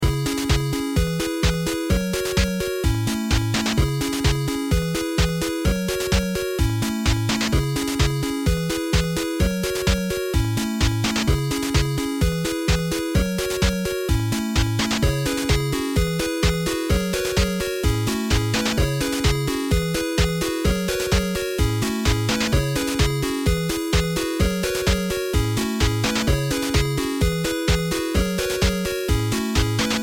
programmatically generated 8-bit musical loops